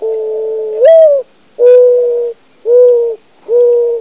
Mourning Dove
Audio Source: Cornell Lab of Ornithology
mdove.wav